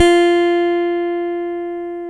Index of /90_sSampleCDs/Roland L-CD701/BS _Rock Bass/BS _Chapmn Stick